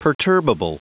Prononciation du mot perturbable en anglais (fichier audio)
Prononciation du mot : perturbable